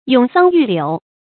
詠桑寓柳 注音： ㄩㄥˇ ㄙㄤ ㄧㄩˋ ㄌㄧㄨˇ 讀音讀法： 意思解釋： 詠的是「桑」，而實際說的是「柳」。喻借題傳情。